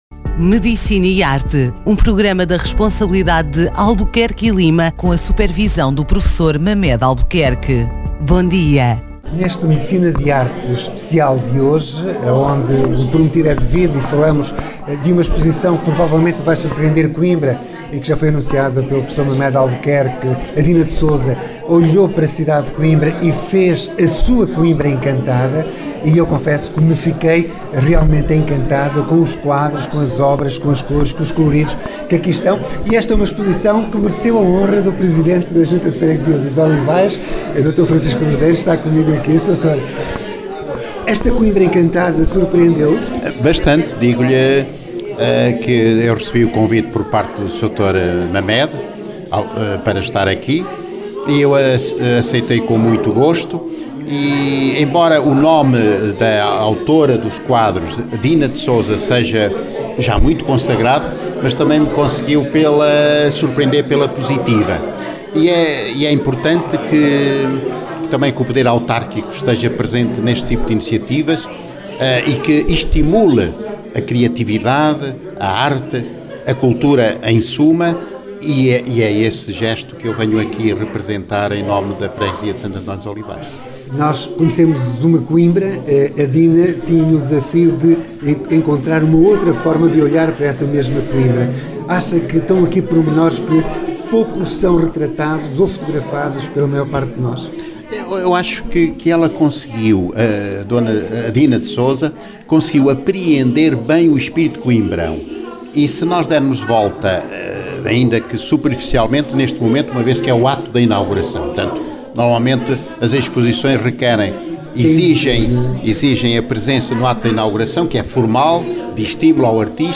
Ouça aqui a reportagem no Medicina E Arte com o Presidente da Junta dos Olivais, Dr. Francisco Rodeiro, diversos convidados